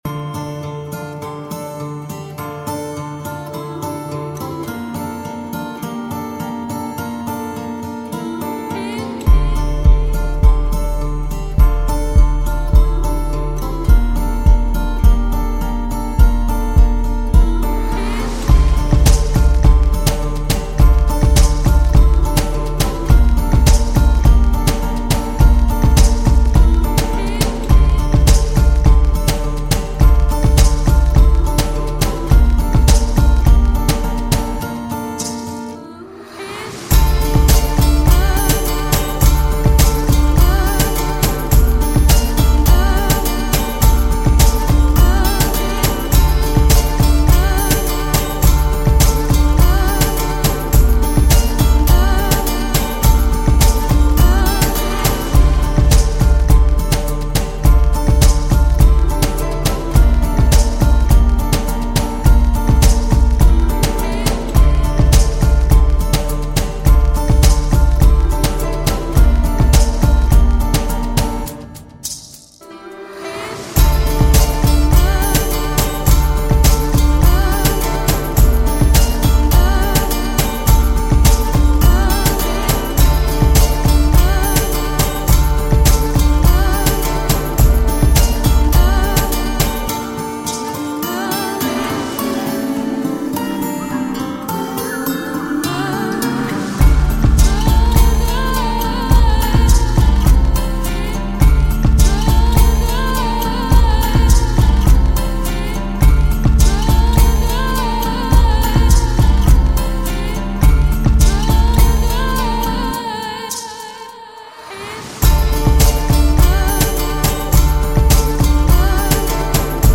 Chosen for the mix’s great rhythm and well-chosen loops.